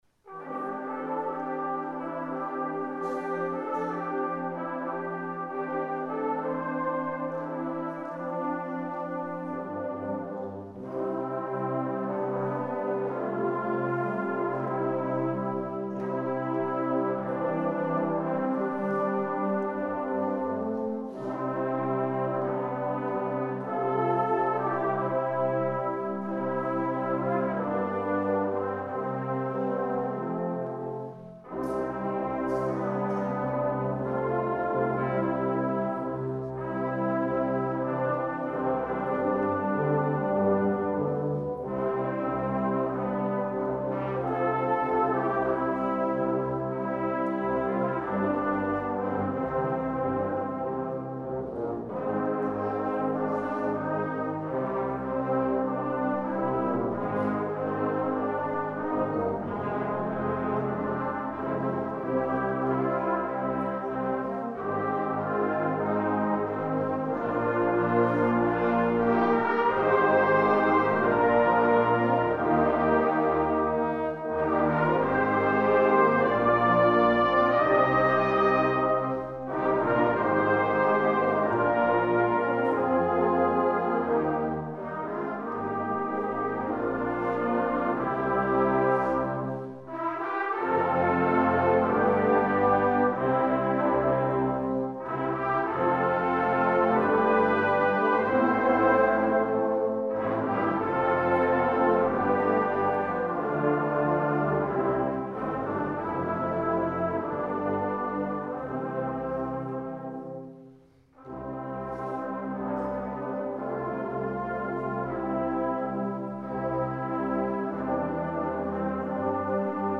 Bläsermusik proben
Die Aufnahmen wurden von verschiedenen Ensembles aufgenommen und zur Verfügung gestellt. Nicht mit dem Anspruch einer perfekten CD-Aufnahme, sondern als Hilfe für Chorleiterinnen und Chorleiter oder einzelne Mitspieler, um sich einen Klangeindruck der Stücke zu verschaffen.
Werner Petersen, Bläser des Bezirks Ortenau, Gloria 2024 S. 36-37